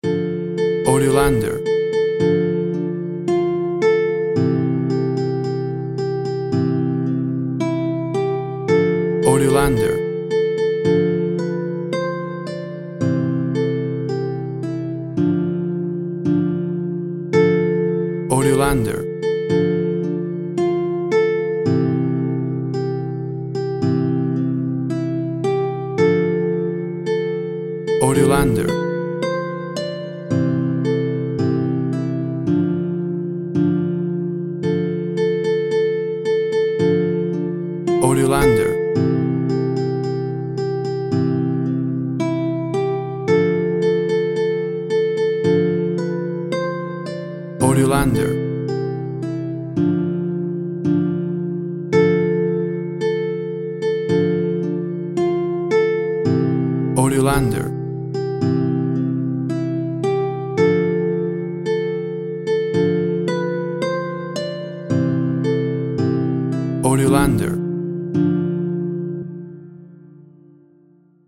WAV Sample Rate 16-Bit Stereo, 44.1 kHz
Tempo (BPM) 115